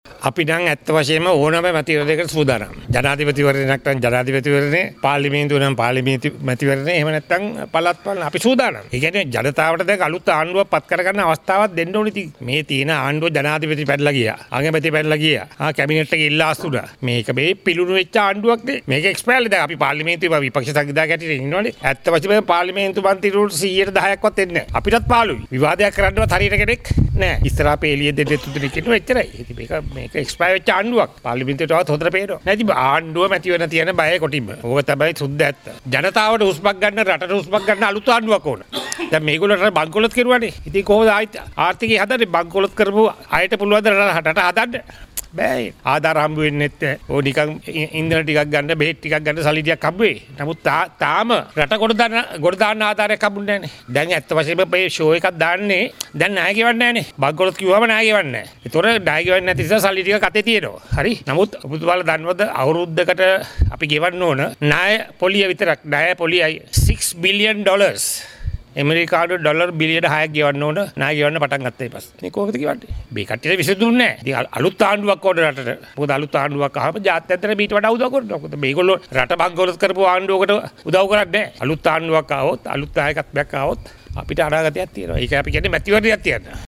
මහනුවරදී මාධ්‍ය වෙත අදහස් පල කරමින් පර්ලිම්නේතු මන්ත්‍රී ලක්ෂ්මන් කිරිඇල්ල මහතා මේ බව අවධරණය කළා .